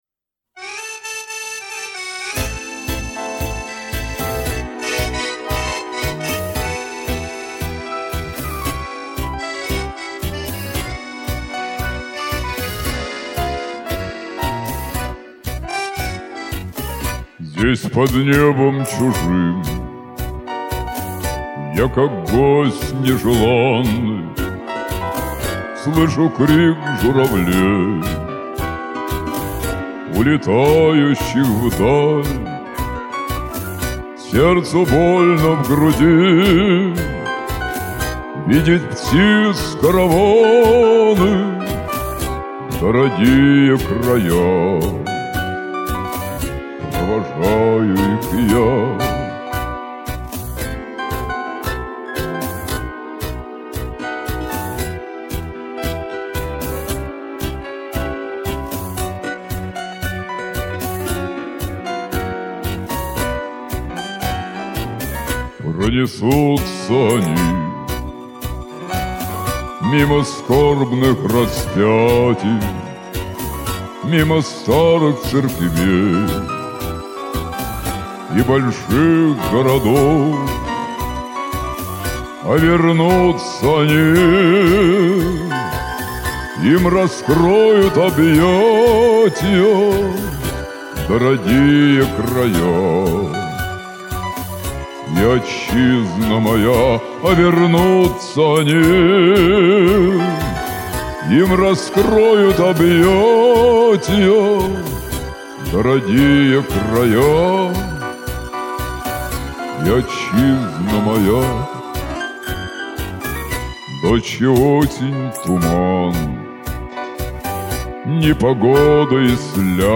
Я ПОЛЬЩЕН ВАШИМ ГОЛОСОМ.